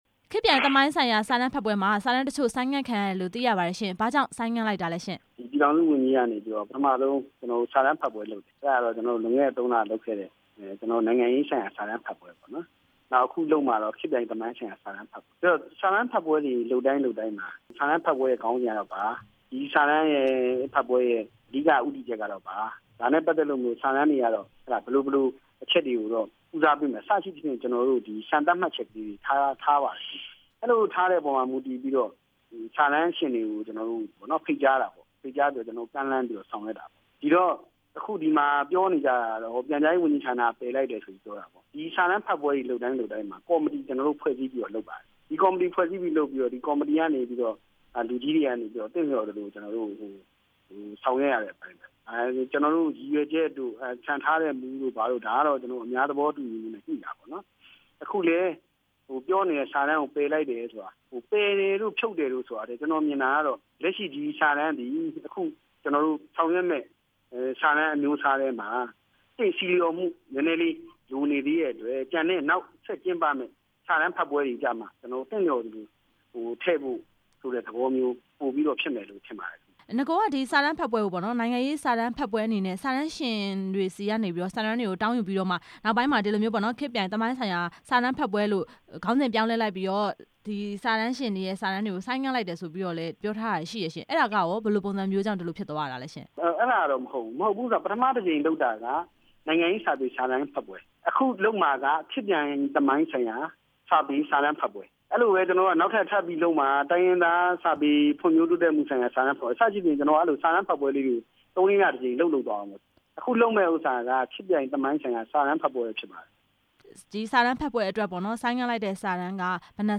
ပြန်ကြားရေးဝန်ကြီးဌာန ဒုတိယ အမြဲတမ်းအတွင်းဝန် ဦးမျိုးမြင့်မောင်နဲ့ မေးမြန်းချက်